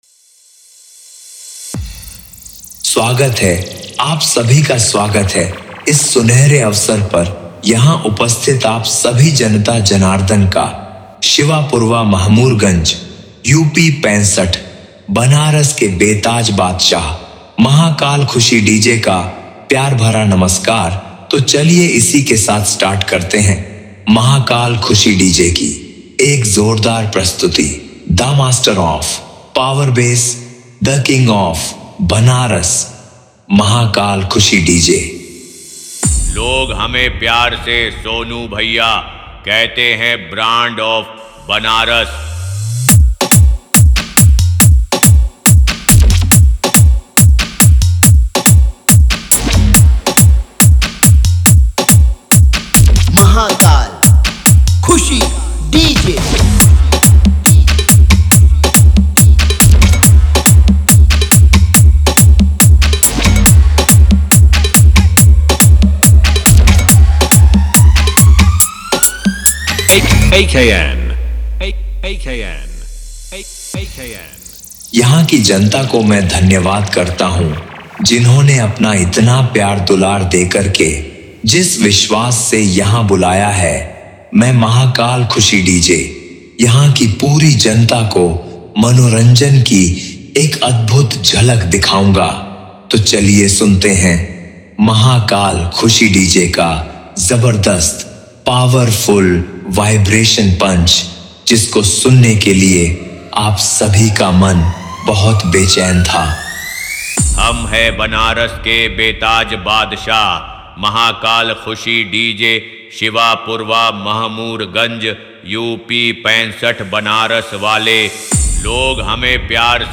Competition DJ Beat
Bass Boosted DJ Mix, Mahakaal Bhajan Remix, EDM DJ Song